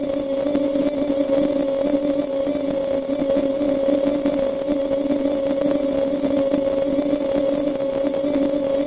描述：粒状合成颗粒
标签： 合成 谷物 粒状
声道立体声